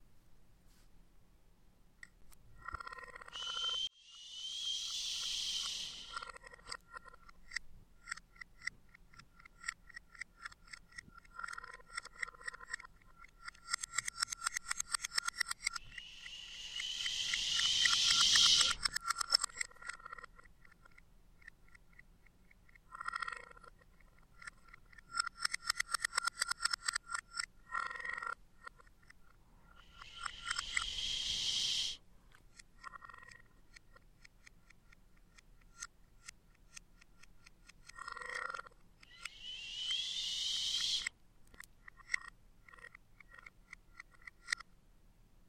素描1的声音" Shh
描述：人声的嘘声。
Tag: 语音 MTC500-M002-S13 耳语